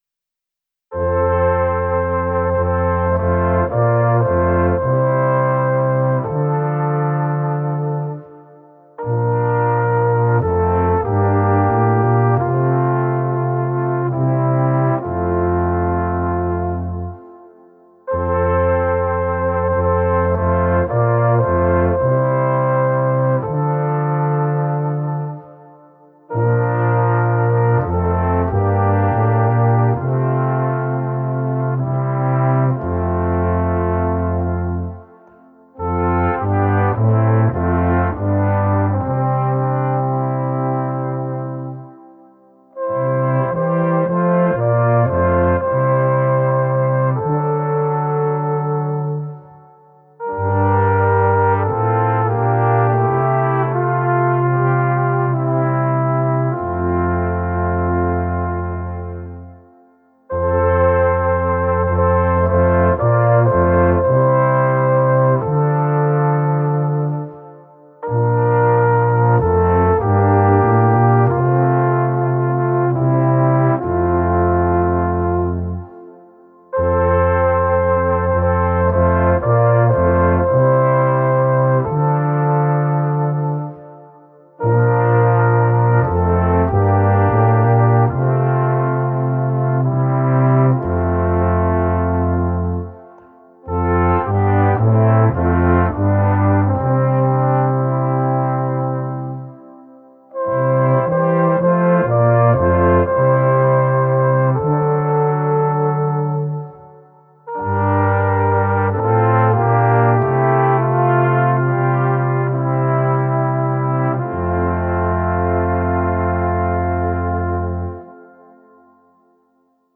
Gattung: Weihnachtliche Weisen für 4 oder 5 Blechbläser